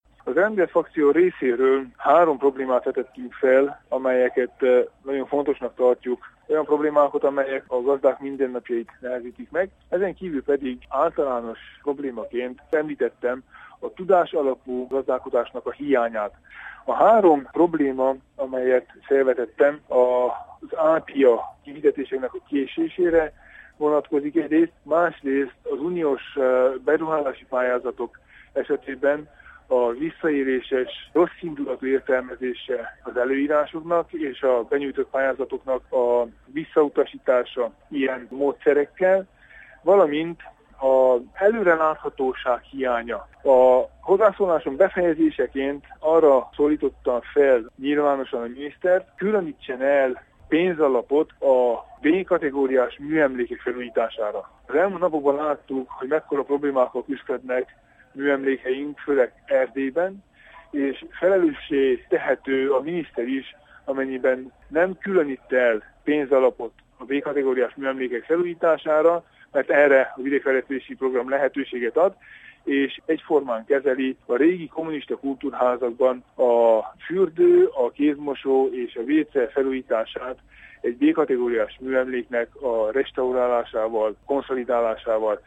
Hallgassa meg Tánczos Barna nyilatkozatát:
tanczos-barna-nyilatkozata_1.mp3